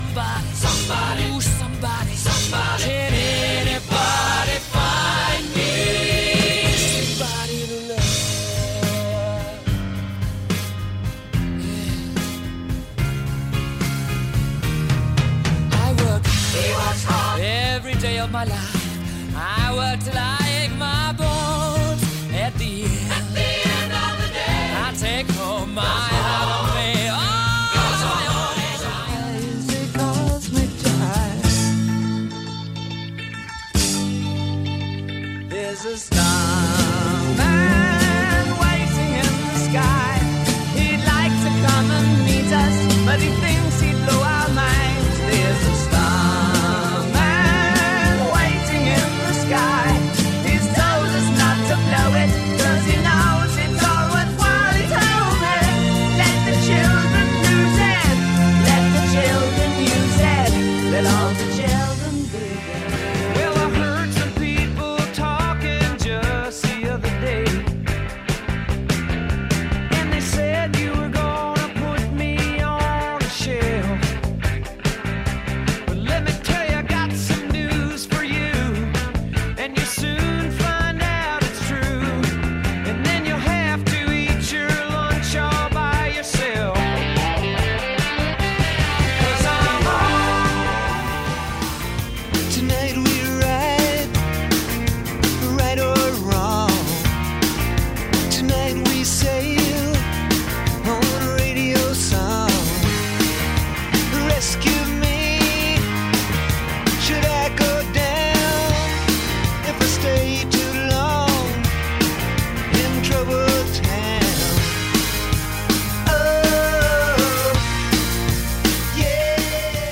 Classic Rock
Best Of Classic Rock